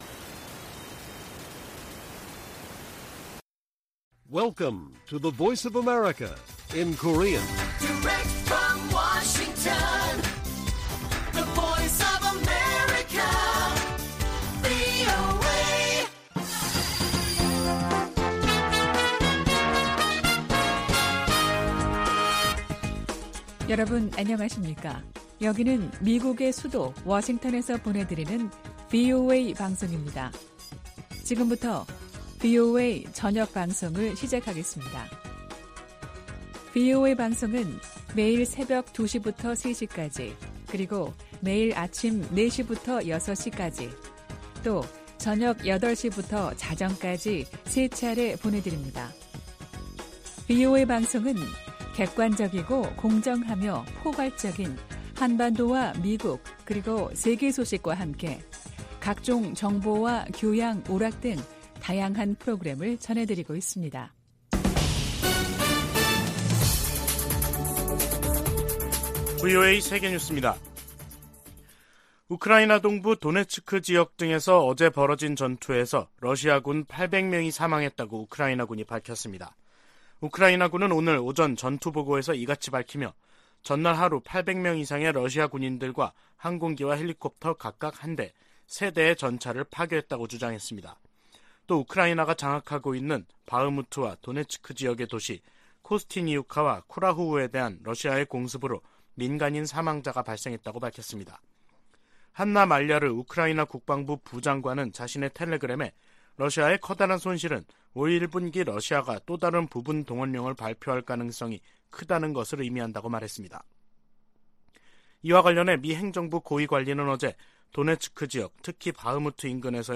VOA 한국어 간판 뉴스 프로그램 '뉴스 투데이', 2023년 1월 5일 1부 방송입니다. 한국 국가정보원은 북한 무인기의 용산 대통령실 촬영 가능성을 배제할 수 없다고 밝혔습니다. 미국은 북한 정권의 핵 무력 추구를 면밀히 주시하며 미한일 군사협력 강화를 계속 모색할 것이라고 백악관 고위관리가 밝혔습니다.